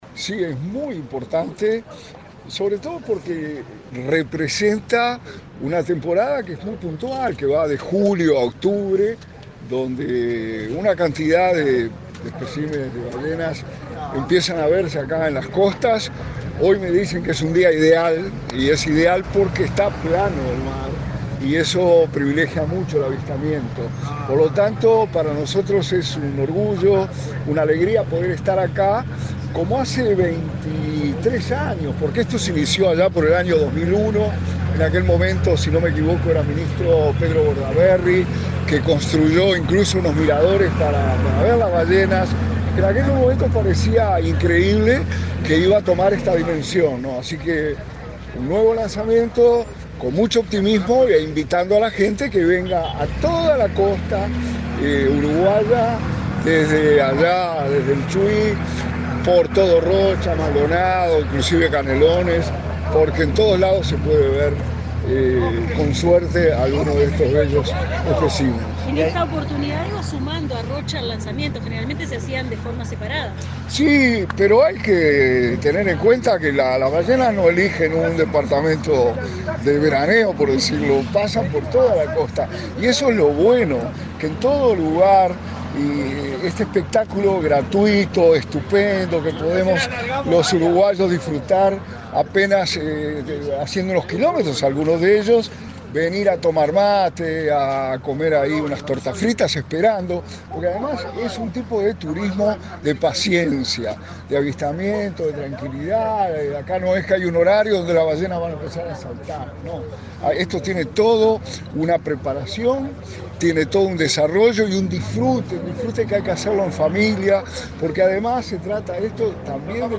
Declaraciones del subsecretario de Turismo, Remo Monzeglio
El subsecretario de Turismo, Remo Monzeglio, dialogó con la prensa, antes de participar en el lanzamiento de la temporada de avistamiento de ballenas.